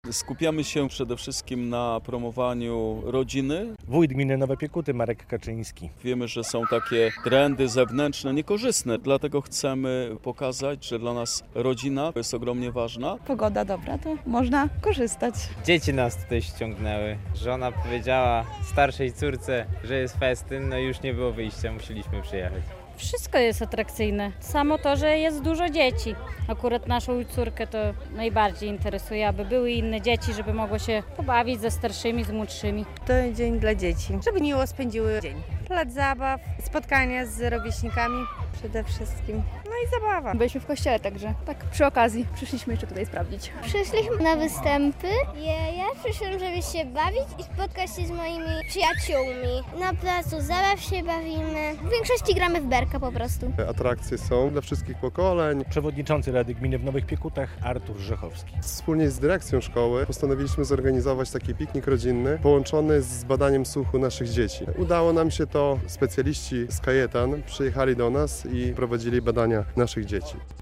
Warsztaty, występy i konkursy - rodzinny piknik ekologiczny w Nowych Piekutach